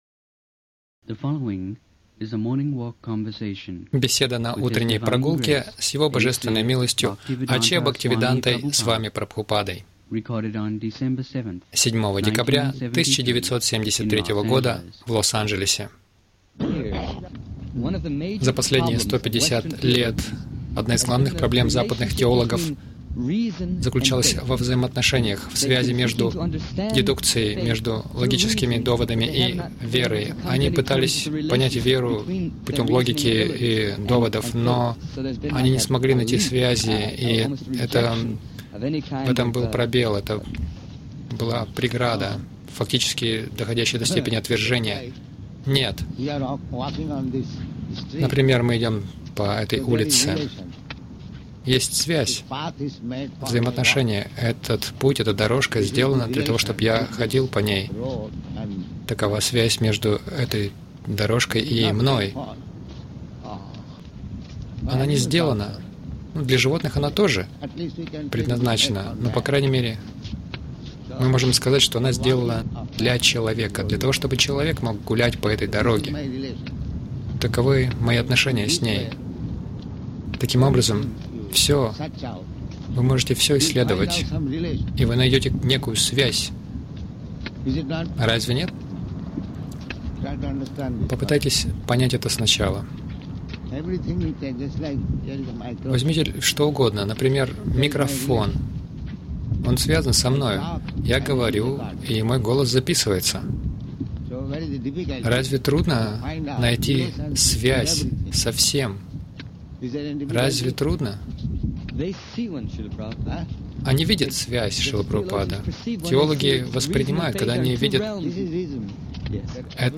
Милость Прабхупады Аудиолекции и книги 07.12.1973 Утренние Прогулки | Лос-Анджелес Утренние прогулки — Прогресс невежества Загрузка...